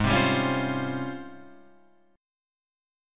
G#M13 chord